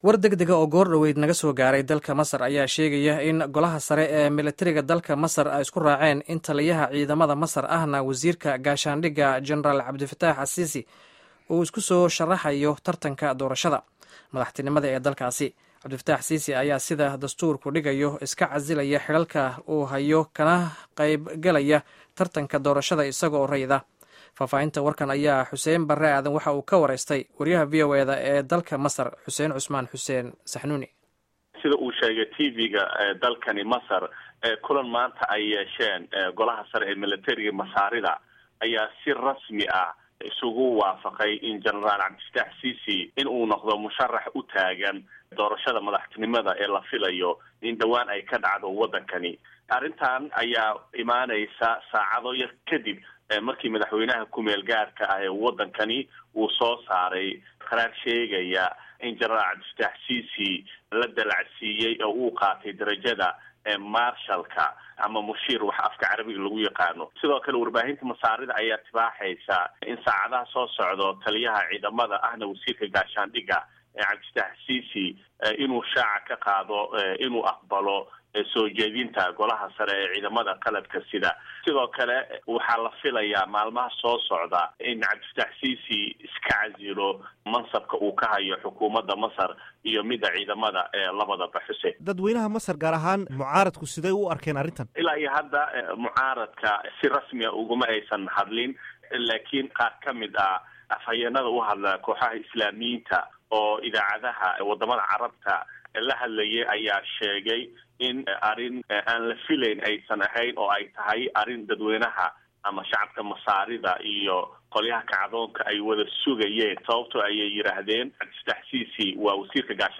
Waraysiga Janaraal Siisi